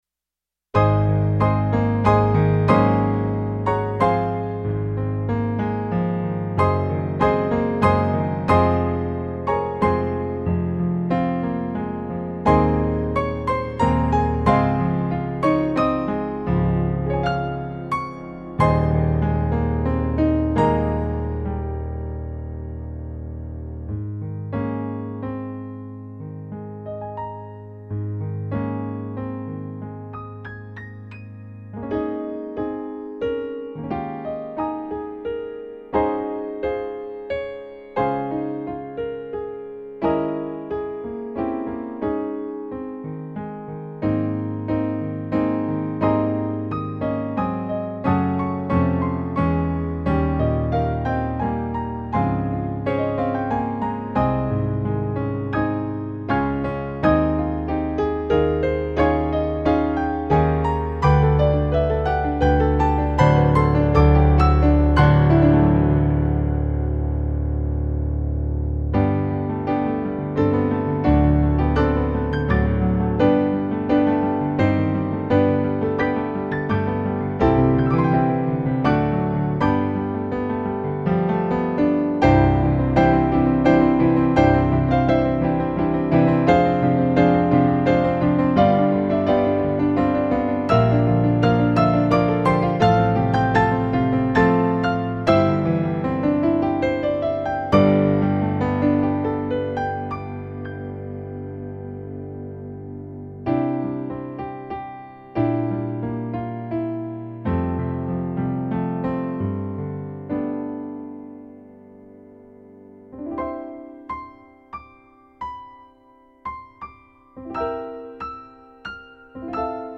One piano, four hands.